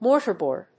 mórtar・bòard